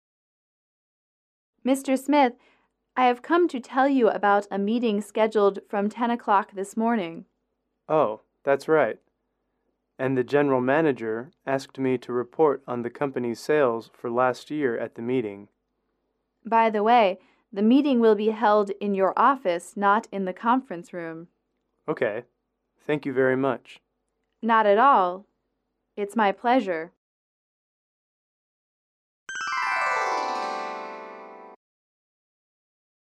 英语主题情景短对话21-3：提醒开会（MP3）